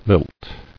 [lilt]